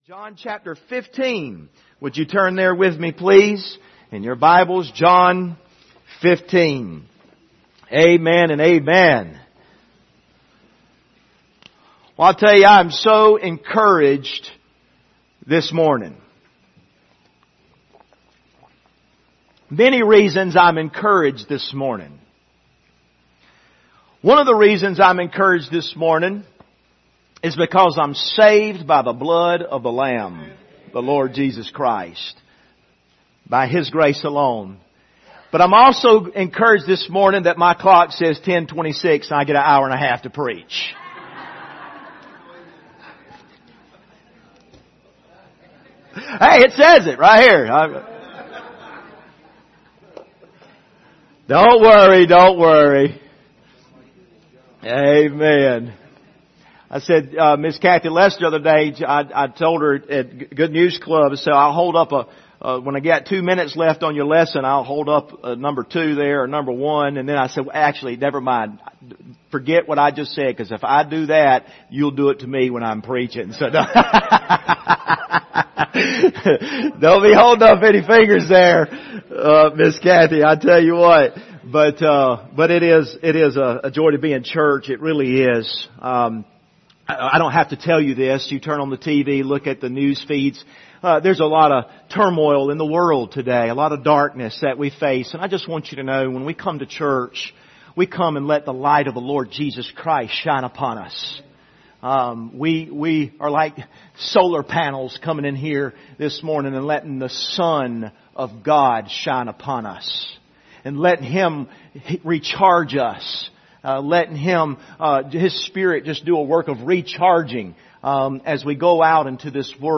The Abiding Life Passage: John 15:18-24 Service Type: Sunday Morning « What Do We Do When The Vessel Is Broken?